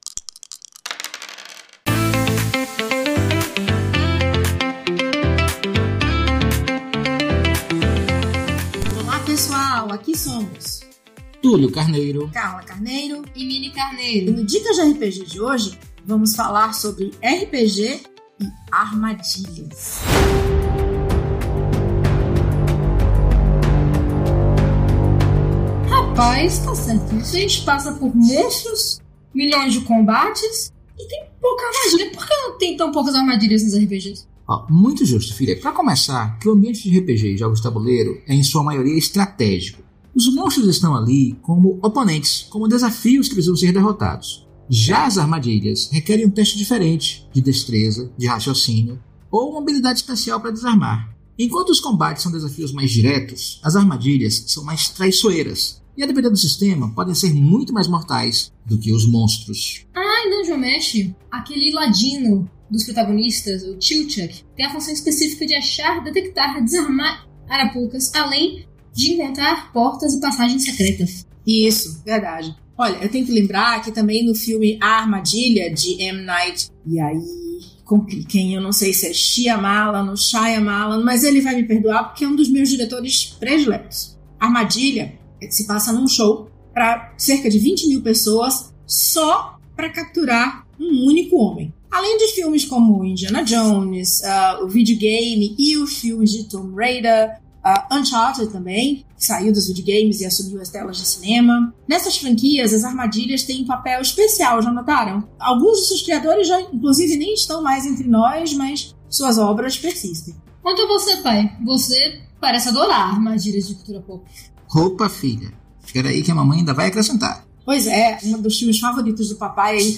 O Dicas de RPG é um podcast semanal no formato de pílula que todo domingo vai chegar no seu feed.
Músicas: Music by from Pixabay